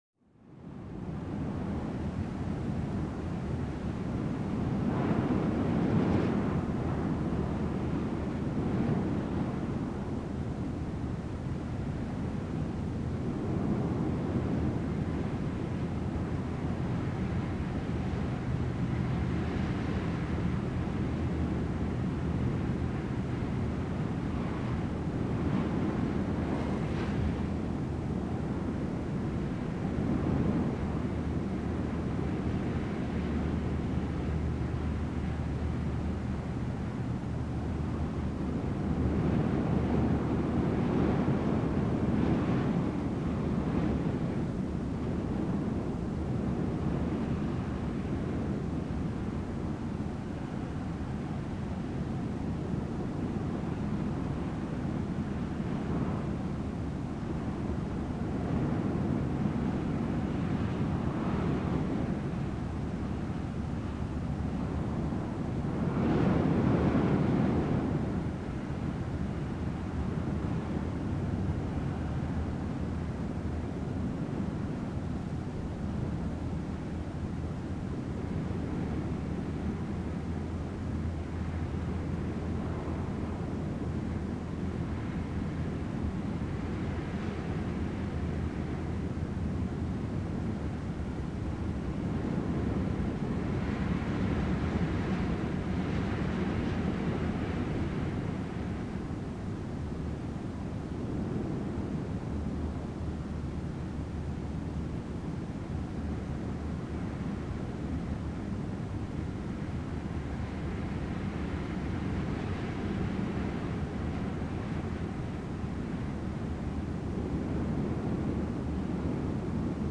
Легкий ветерок